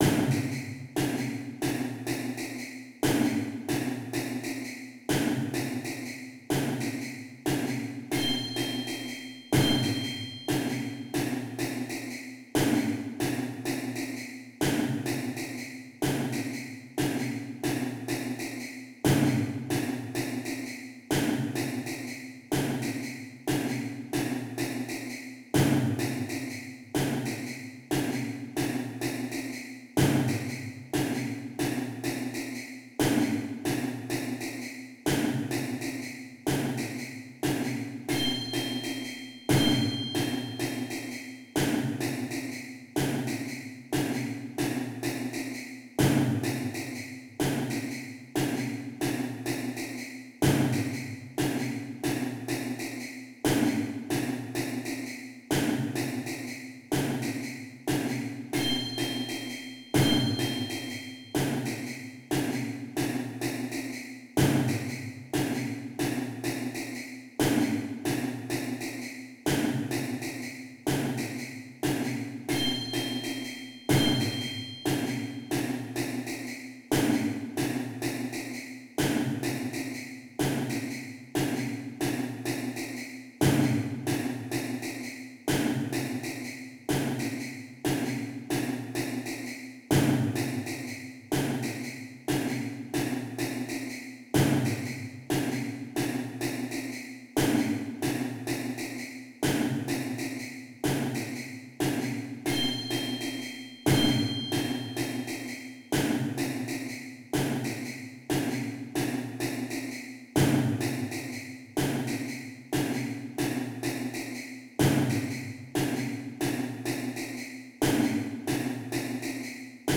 Die Klicktracks entsprechen dem Verlauf des Stückes ohne Wiederholungen nach einem kurzen Vorlauf (siehe dazu und zur Synchronisation der Klicktracks mit dem Notentext die Erläuterungen in der Partitur).
Zum Üben im schnelleren Tempo sind noch Klicktracks für 10%, 20% und 33% schnelleres Tempo zum Herunterladen bereitgestellt.
Alle Stimmen zusammen, 20% schneller